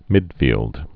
(mĭdfēld)